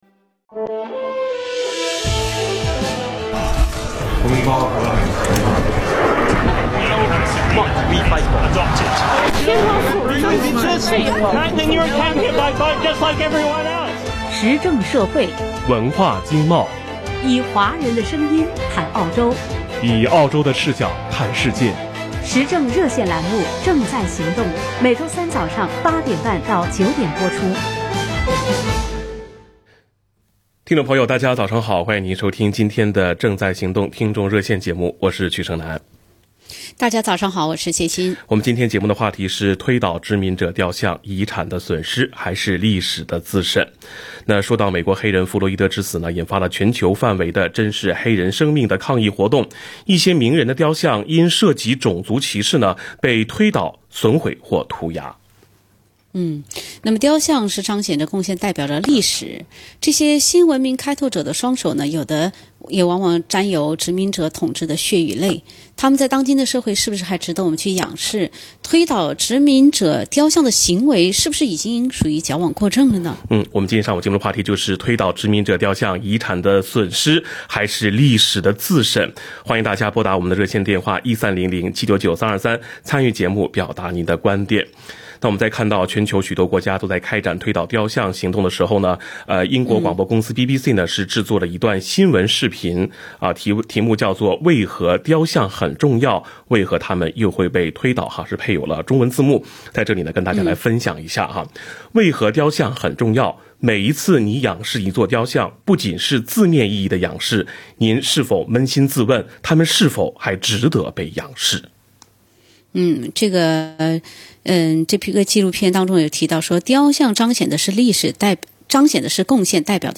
action_talback_june_17_0.mp3